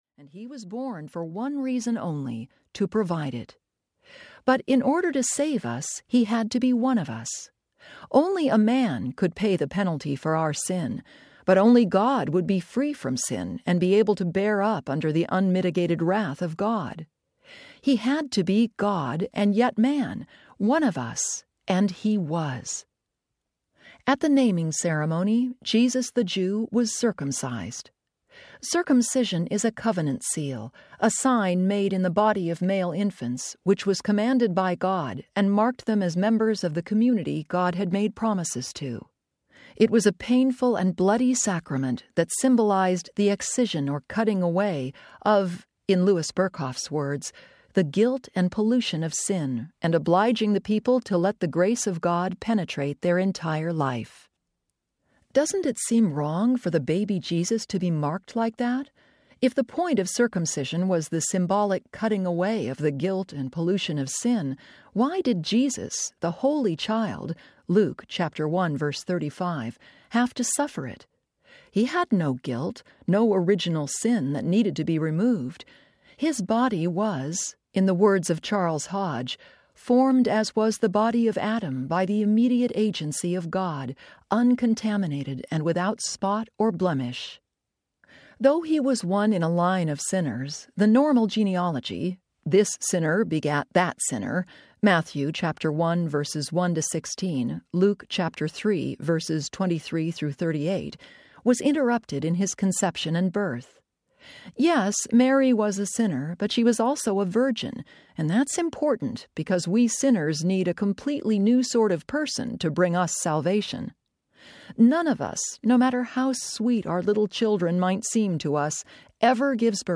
Found in Him Audiobook
Narrator
7.25 Hrs. – Unabridged